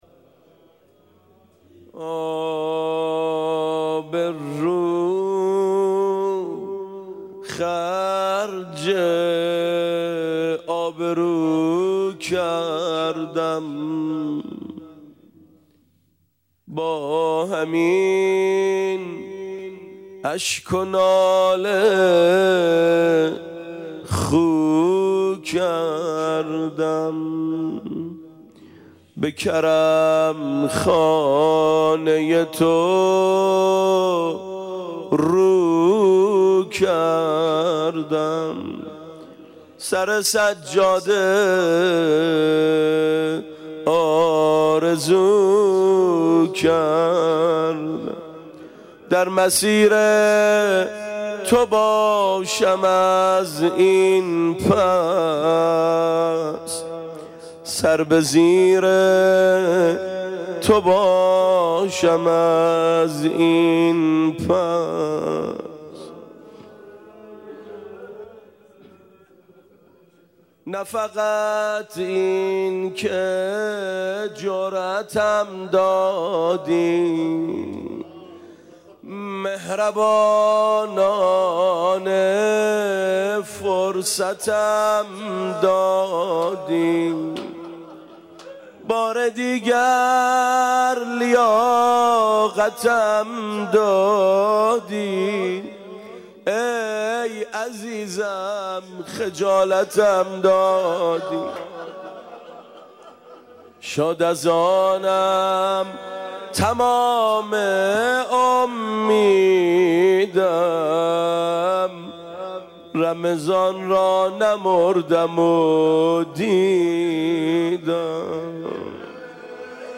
روضةالشهداء